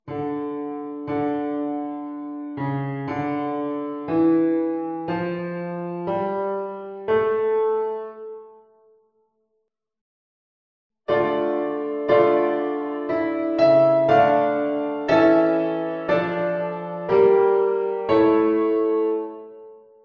Harmonie houdt zich bezig met het verbinden van (in de regel: vierstemmige) akkoorden.
uitwerking van een gegeven bas